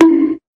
bonk.wav